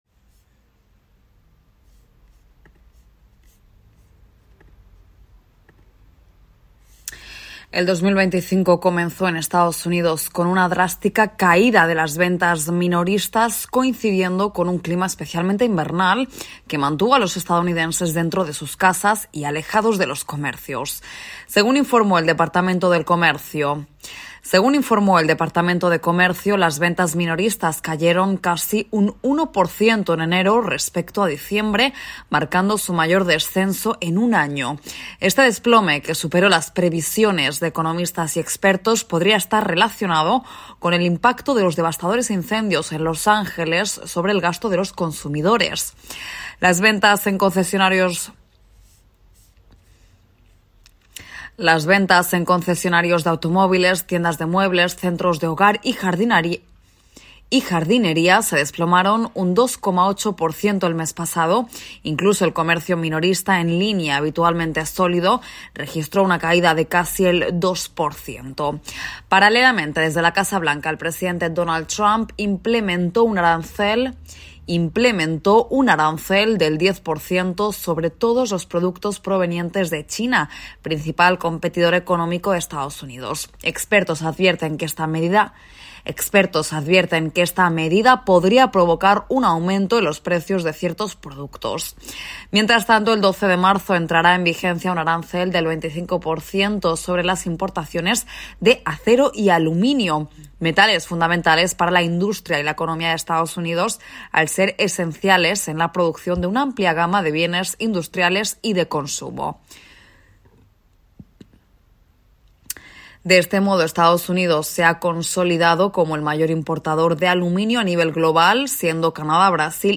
AudioNoticias